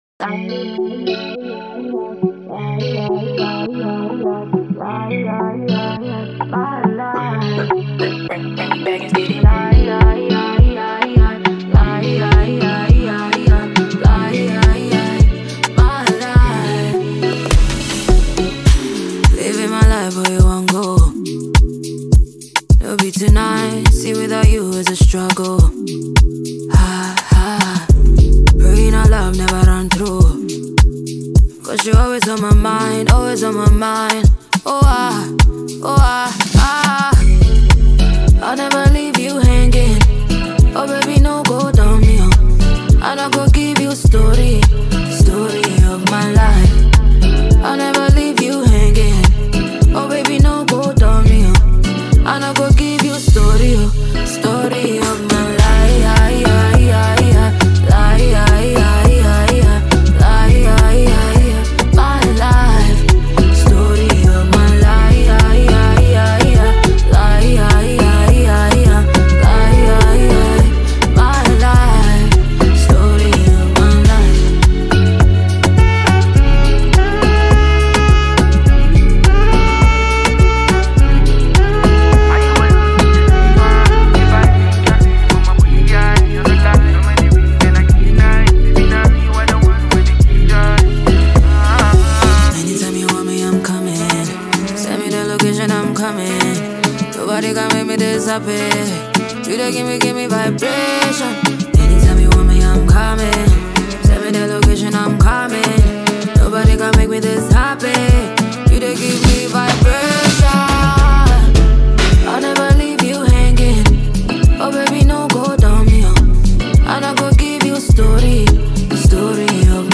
Ghana’s versatile and soulful hitmaker
smooth vocals